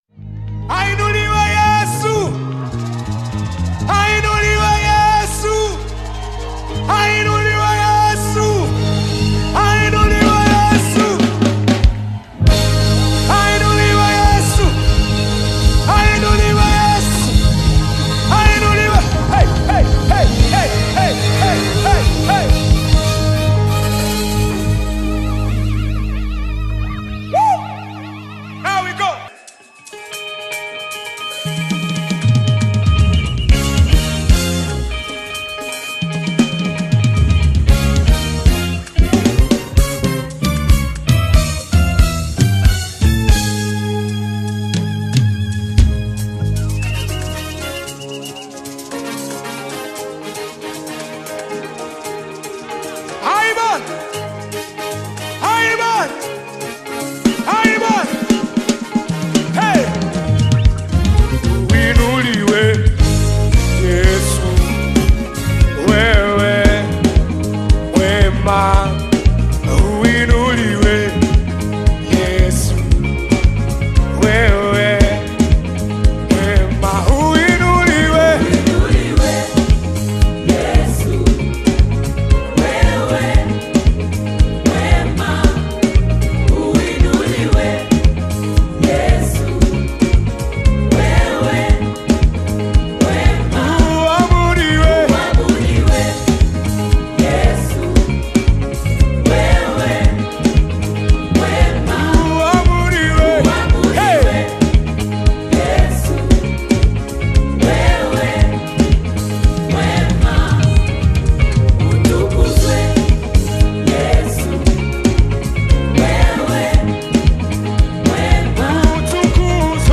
African Music
praise song